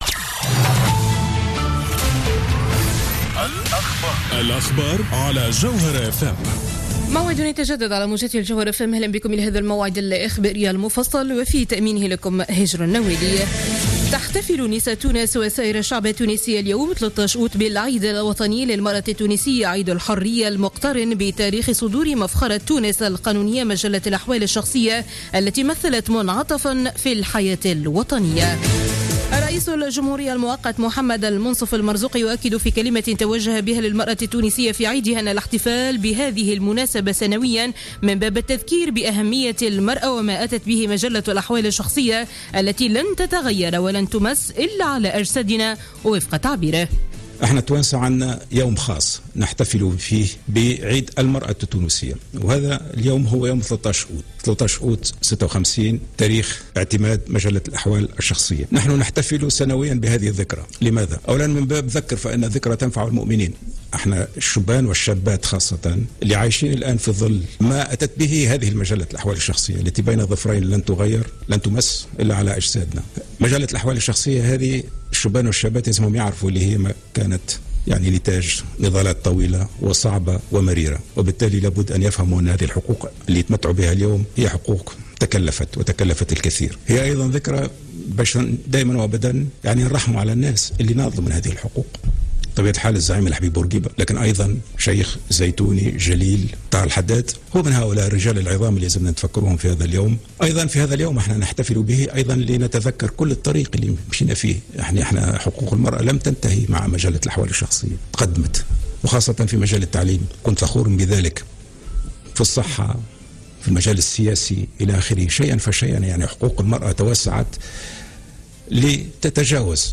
نشرة أخبار منتصف الليل ليوم الاربعاء 13-08-14